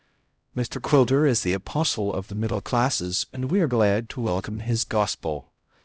xcodec-hubert-librispeech_0.5.wav